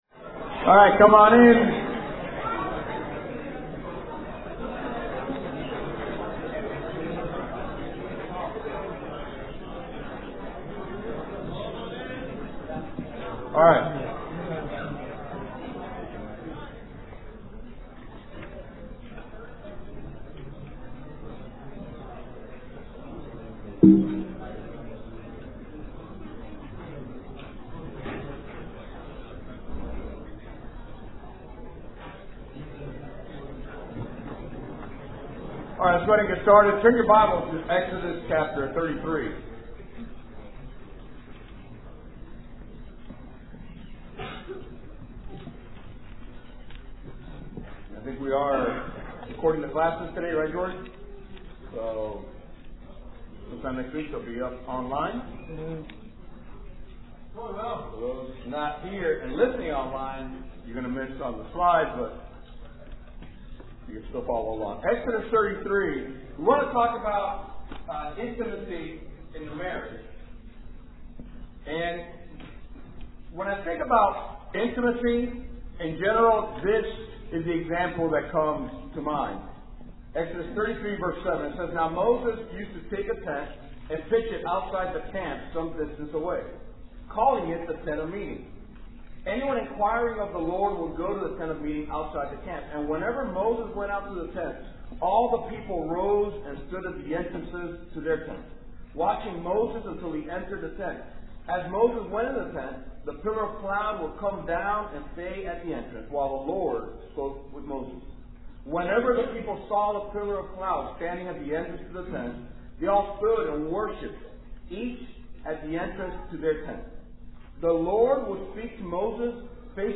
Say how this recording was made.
Palm+Beach+Marriage+Workshop+Class+02.mp3